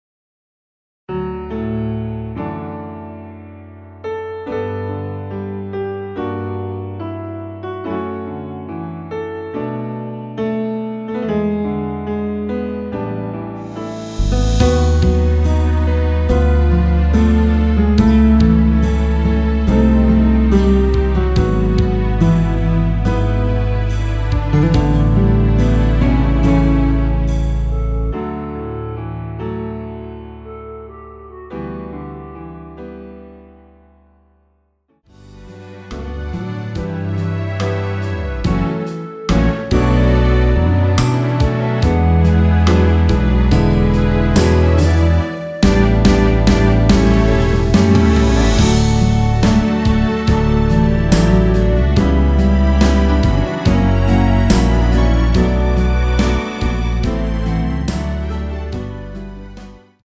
원키에서(-3)내린 멜로디 포함된 MR입니다.(미리듣기 확인)
D
앞부분30초, 뒷부분30초씩 편집해서 올려 드리고 있습니다.
중간에 음이 끈어지고 다시 나오는 이유는